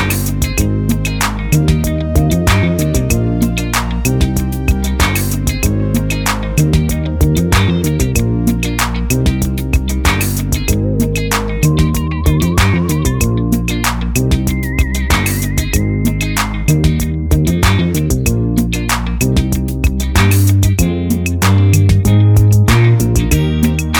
no Backing Vocals Soul / Motown 4:08 Buy £1.50